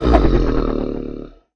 sounds / mob / ravager / stun3.ogg